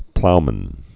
(ploumən)